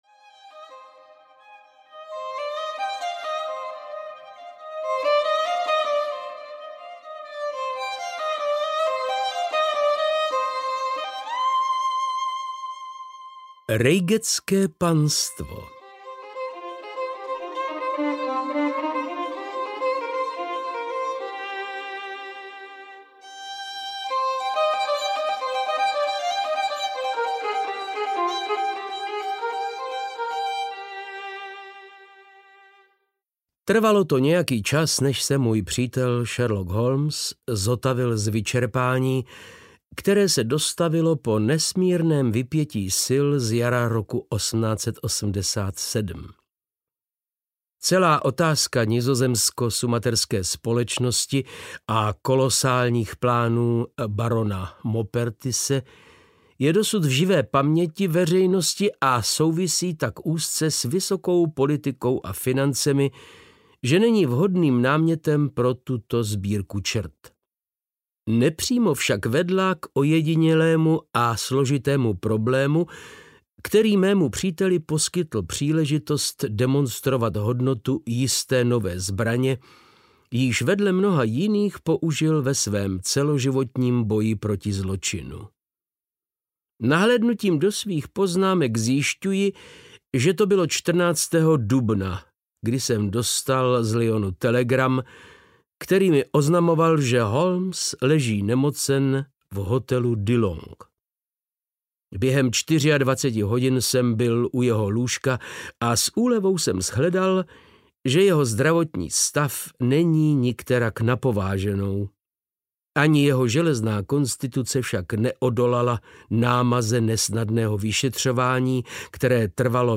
Vzpomínky na Sherlocka Holmese 6 - Reigateské panstvo audiokniha
Ukázka z knihy
• InterpretVáclav Knop